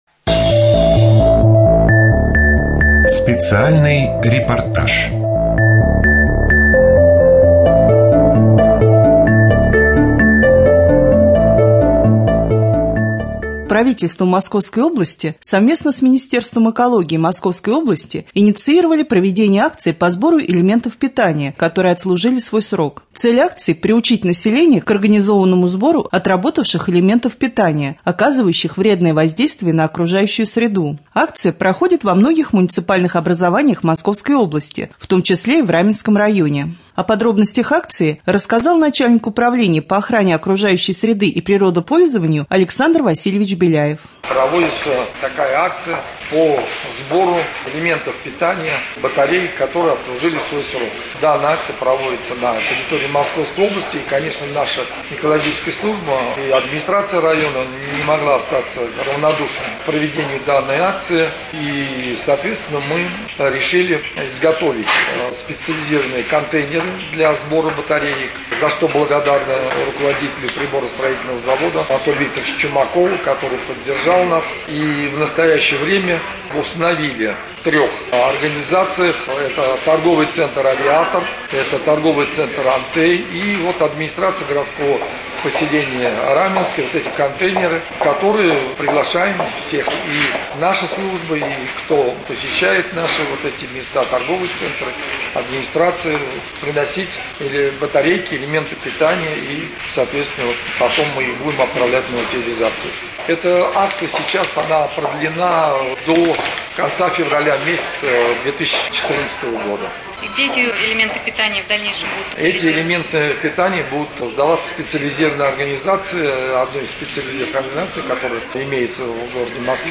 3.Рубрика «Специальный репортаж». В Раменском районе проходит акция по сбору использованных элементов питания.